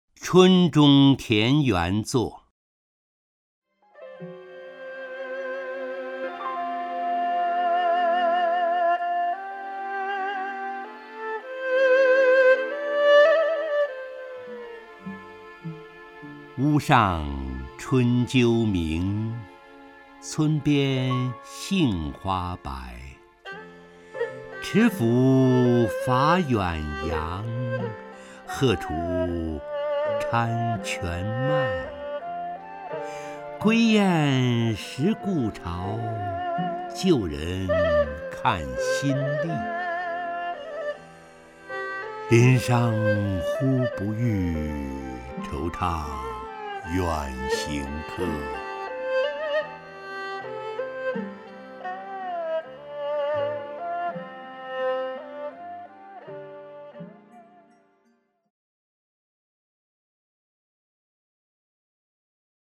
陈醇朗诵：《春中田园作》(（唐）王维) （唐）王维 名家朗诵欣赏陈醇 语文PLUS